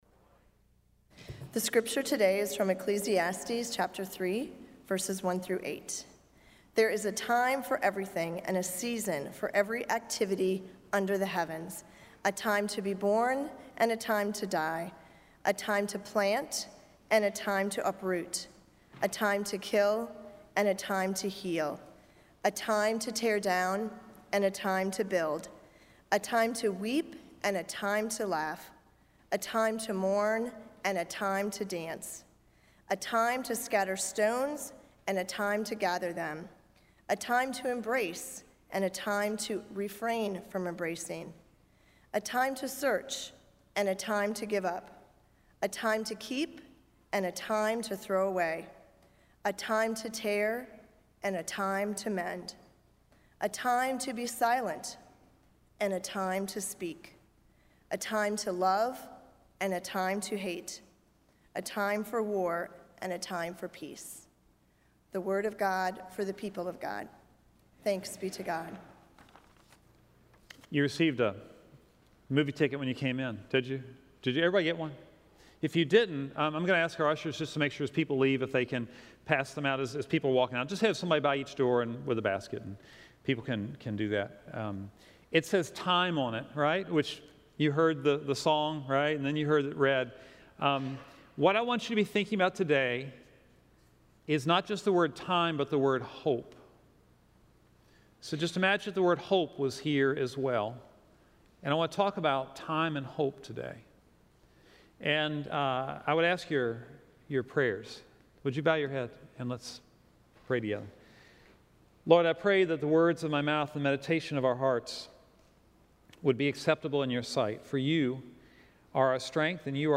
This weeks sermon features The Fellowship of the Ring.